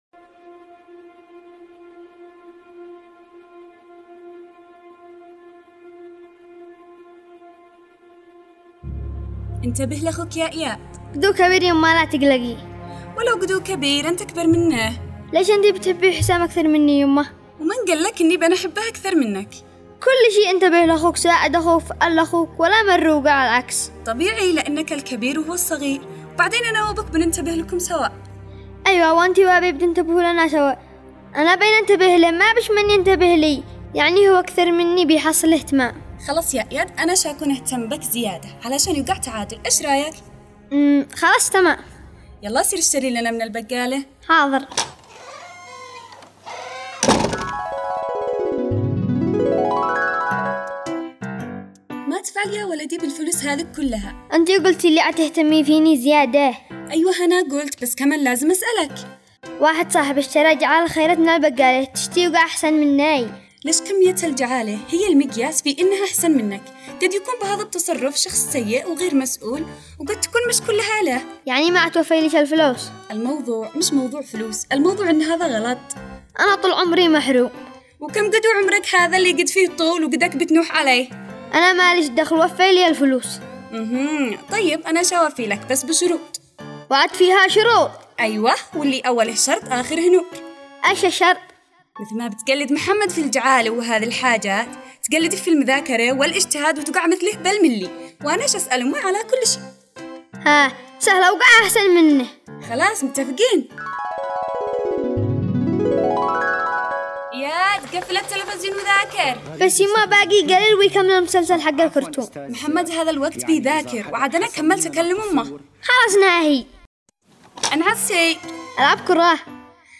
دراما رمضانية